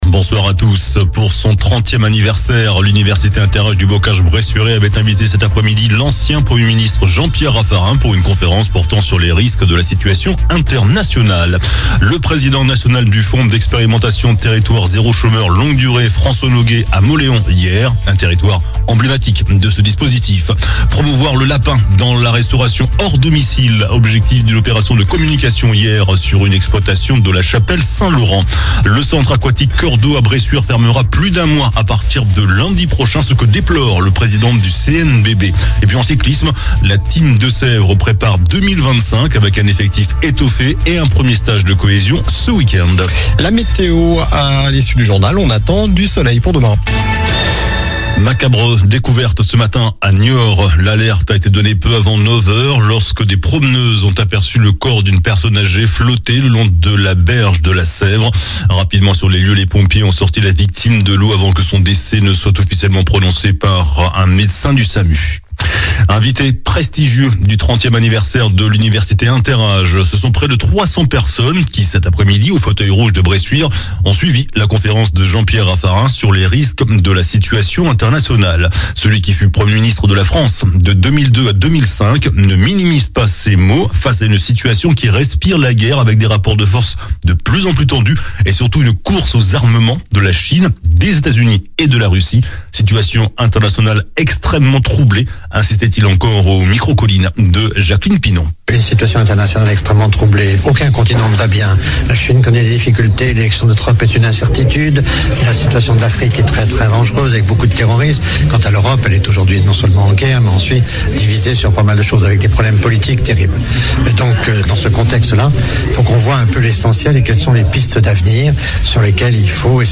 JOURNAL DU JEUDI 28 NOVEMBRE ( SOIR )